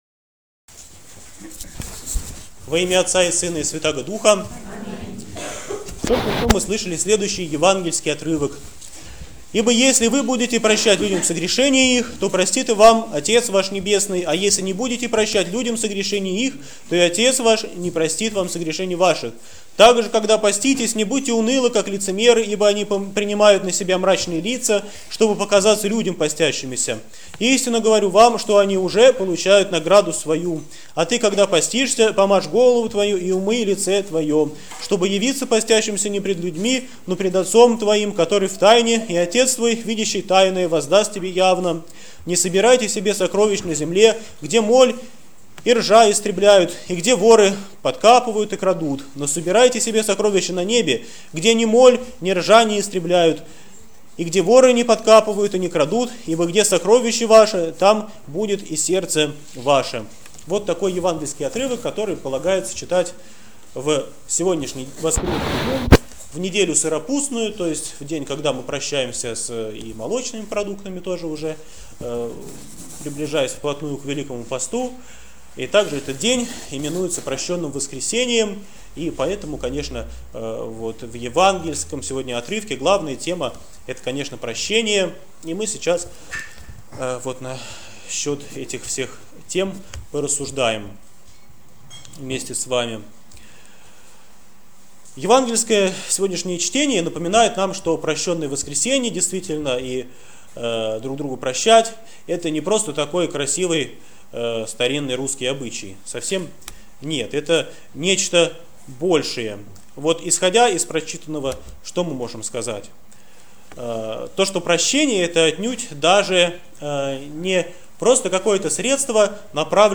Проповедь в Неделю сыропустную06 Март 2014